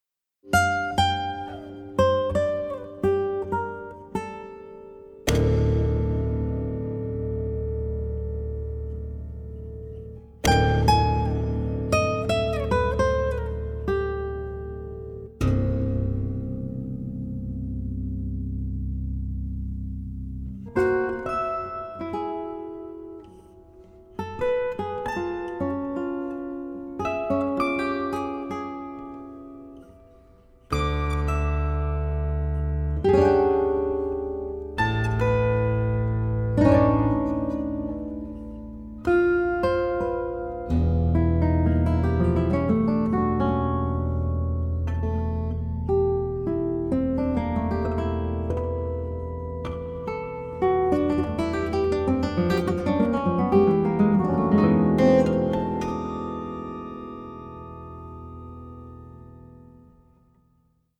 16-string classical guitar